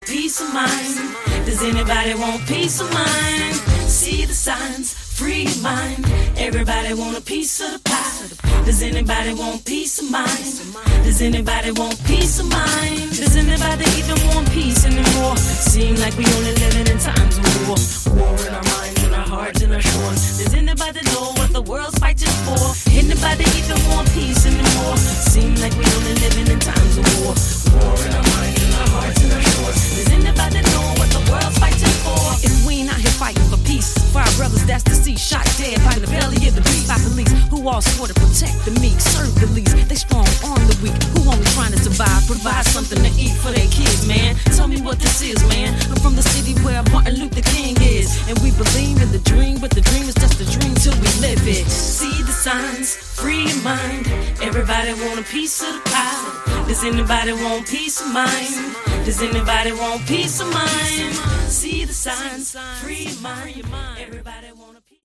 Category: Instrumental Hard Rock